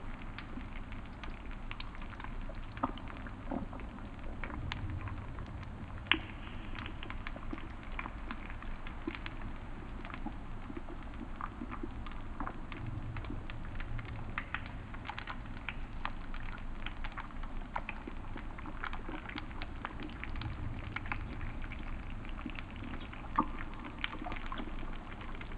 cave_ambience_loop_04.wav